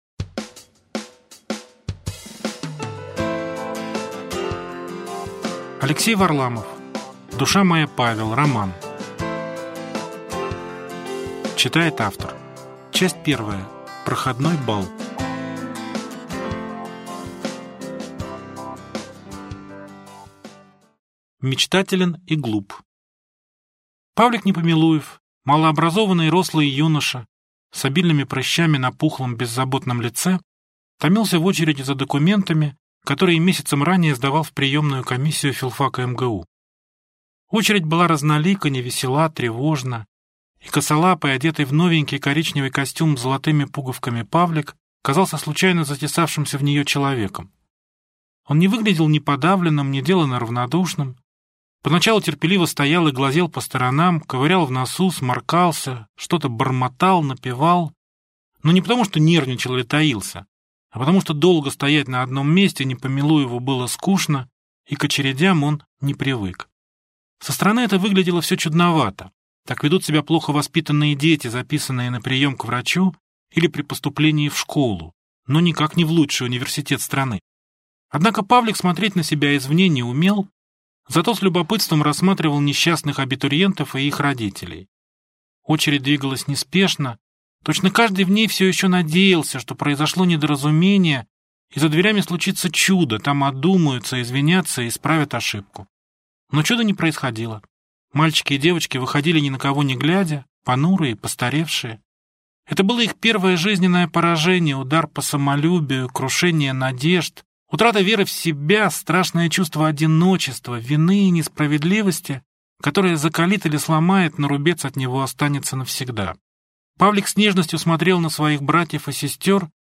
Аудиокнига Душа моя Павел | Библиотека аудиокниг